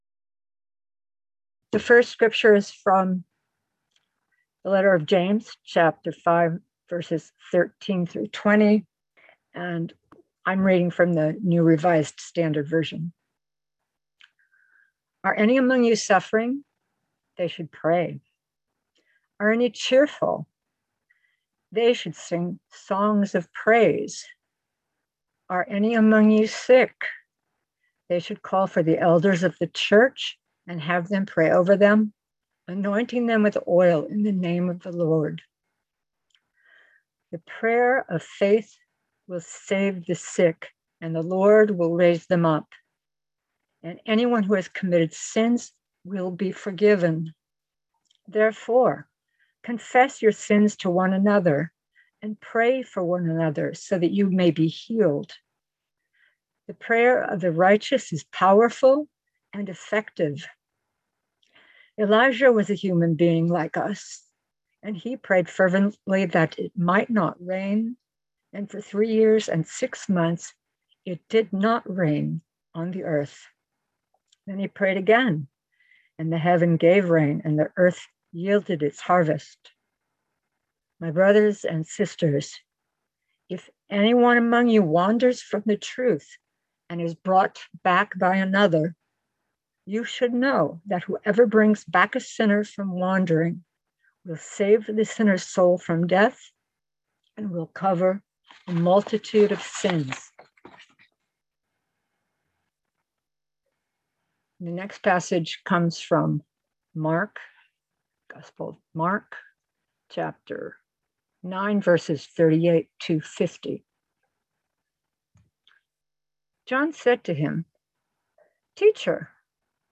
Listen to the most recent message from Sunday worship at Berkeley Friends Church, “The Salty Community.”